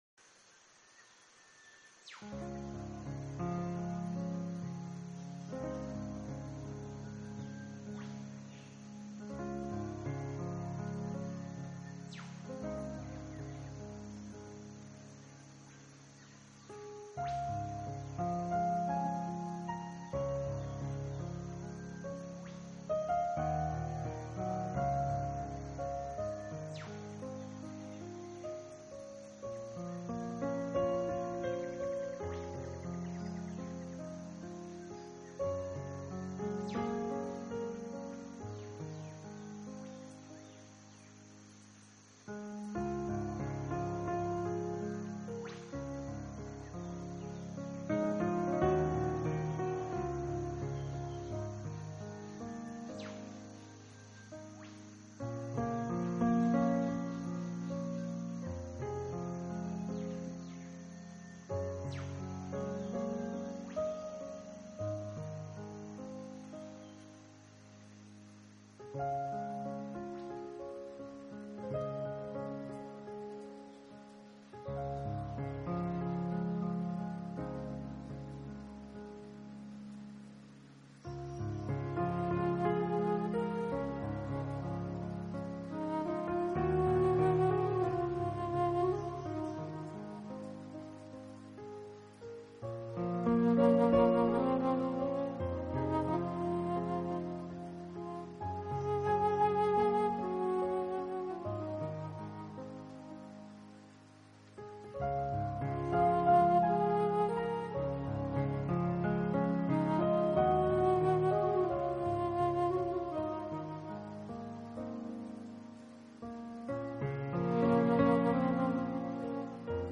以柔和的钢琴和吉他为主要乐器，结合夏日下雨的背景录音，创造出美妙地放